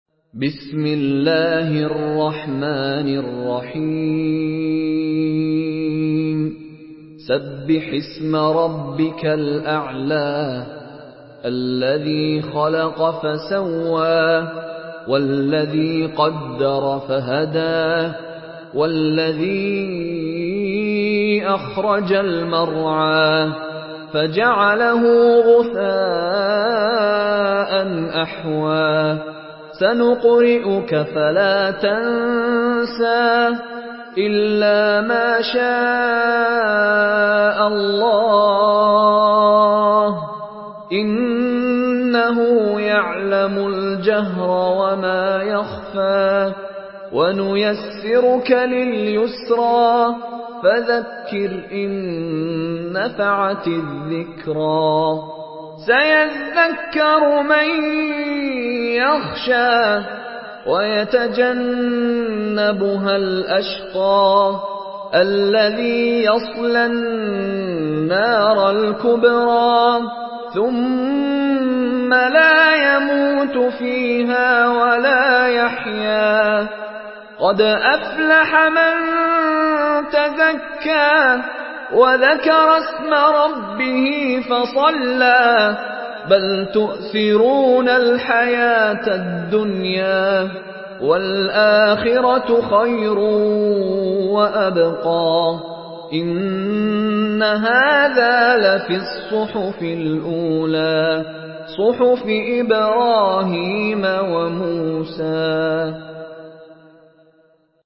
Surah Al-Ala MP3 in the Voice of Mishary Rashid Alafasy in Hafs Narration
Murattal Hafs An Asim